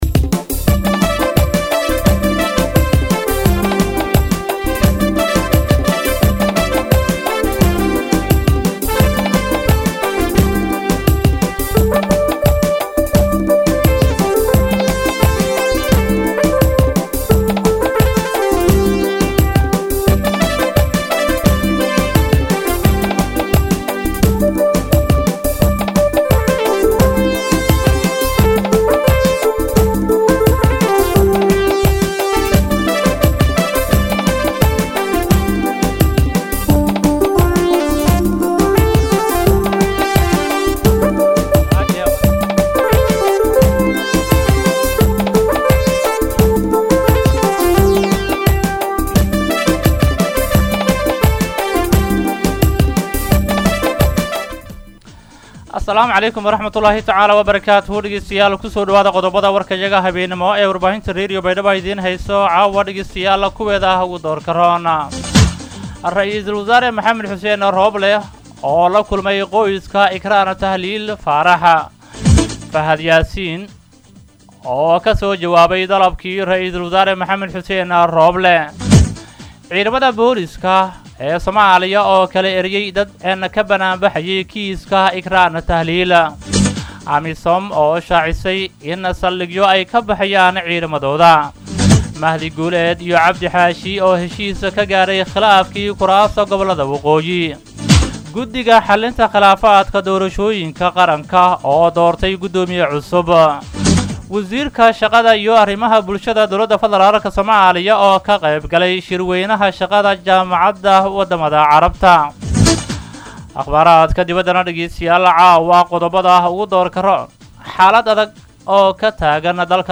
DHAGEYSO:-Warka Habenimo Radio Baidoa 5-9-2021